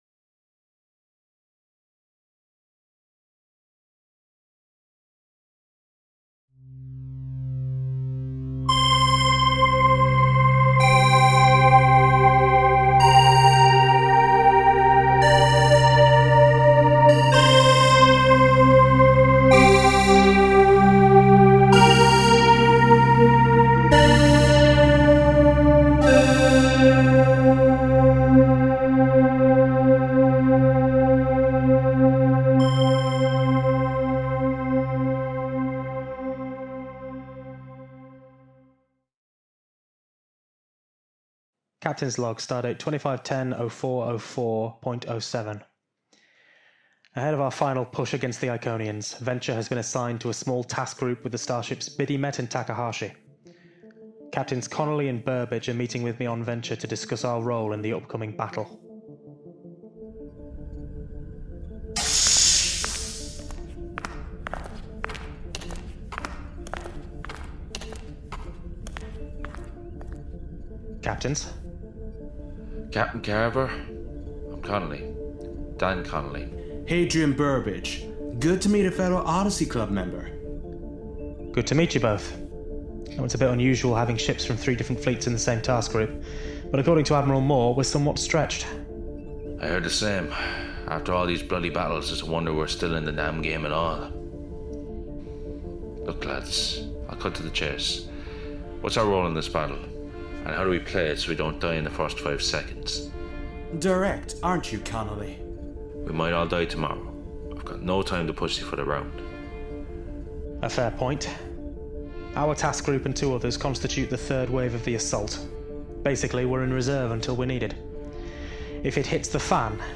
Audio Books/Drama Author(s